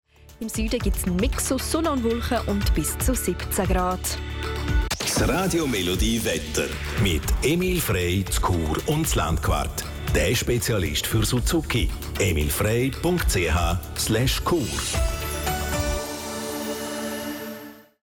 Sponsoring Wetter